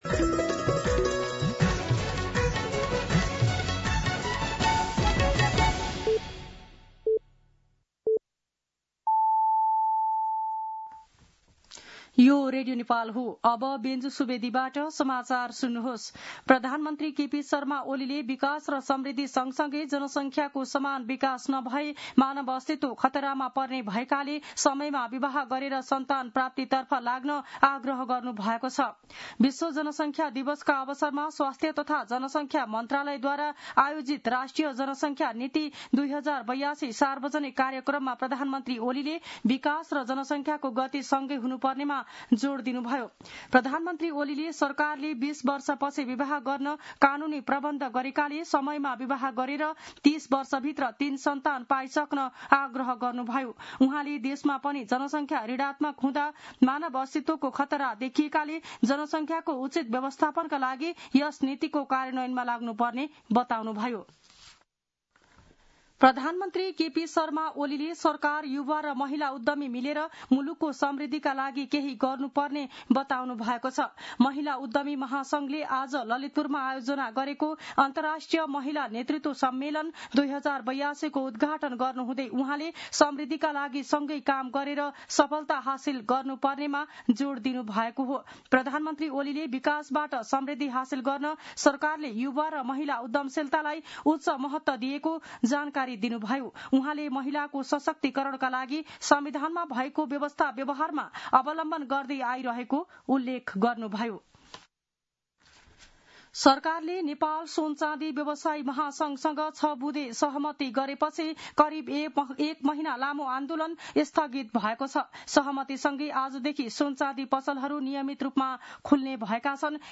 दिउँसो १ बजेको नेपाली समाचार : २७ असार , २०८२
1-pm-News-3-27.mp3